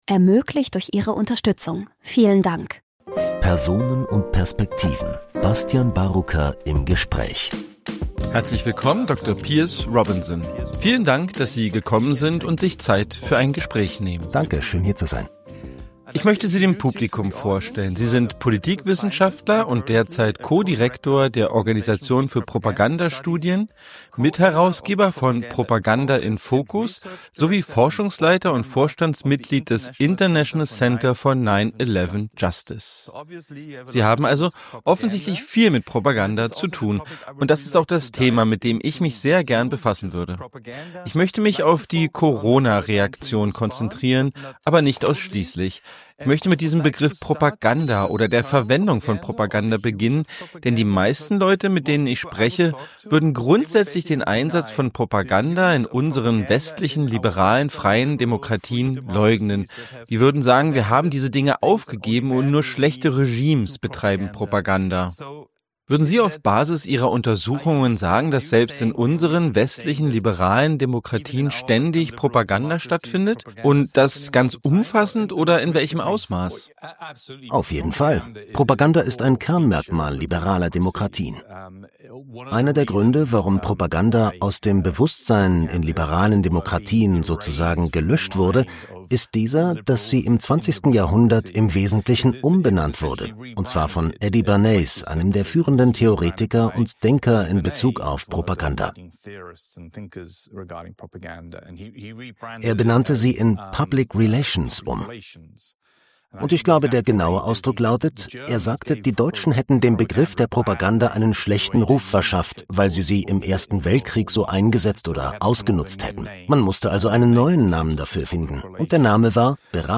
Ich habe mit ihm darüber gesprochen, wie und warum Propaganda auch heute noch eingesetzt wird, um politische Ziele durchzusetzen. Im Detail diskutieren wir die Akteure, die an der globalen Pandemiebekämpfung beteiligt sind, sowie die Gründe für Maßnahmen, die sich als unwirksam erwiesen haben, um die Ausbreitung des Coronavirus zu verlangsamen.